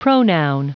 Prononciation du mot pronoun en anglais (fichier audio)